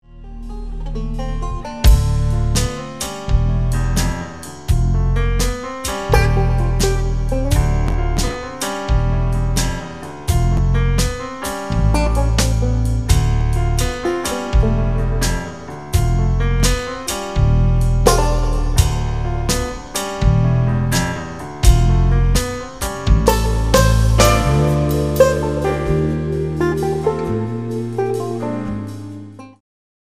bass
horns
percussion
modern jazz